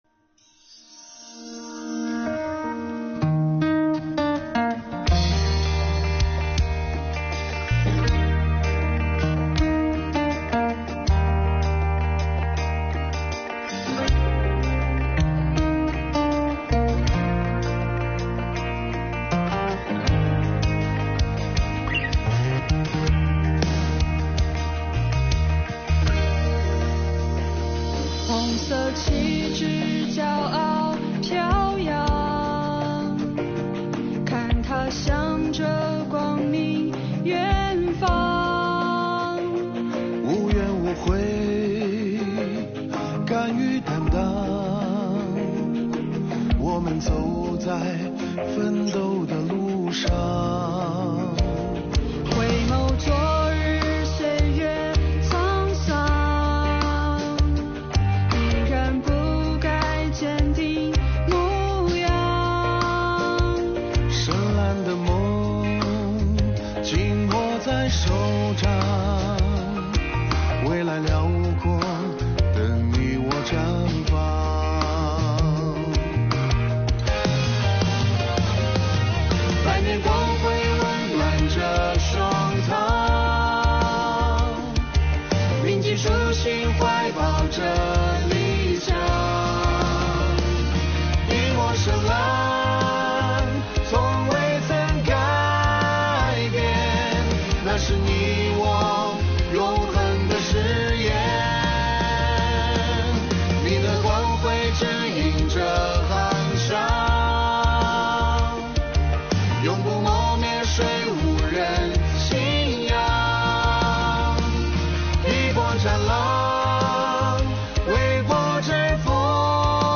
新疆税务干部自己作词作曲并演唱的歌曲《光辉》，唱响税务人跟党走、守初心的时代主旋律，展现了新时代税务人的精神风貌。